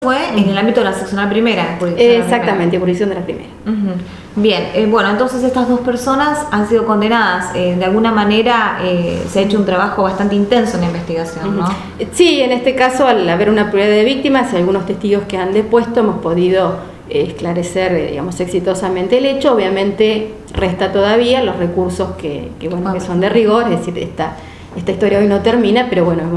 Audios fiscal Marcela Pérez: